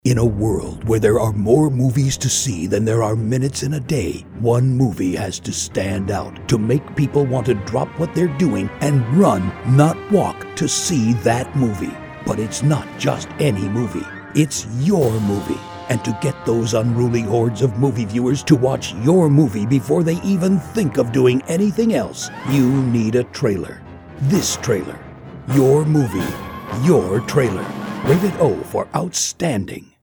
Movie Trailer Demo